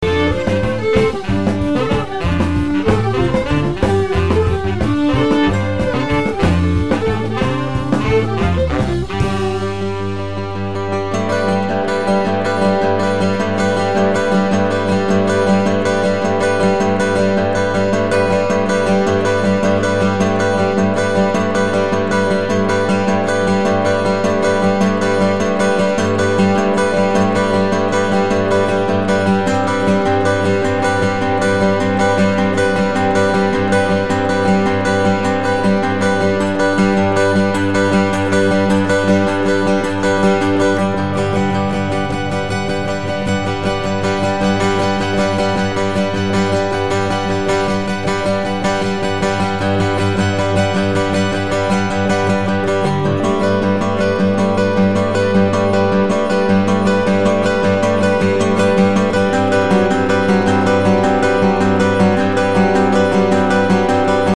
Catalogado quase sempre como pós-rock